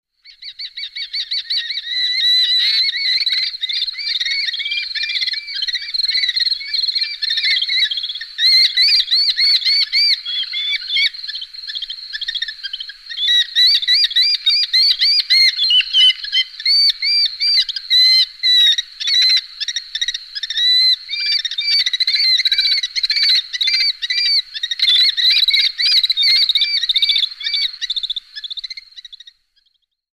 Вы услышите разнообразные голосовые вариации: от характерных резких криков до более мягких перекличек. Записи сделаны в дикой природе разных регионов, что позволяет оценить особенности вокализации птицы.
Голос кобчика: Falco vespertinus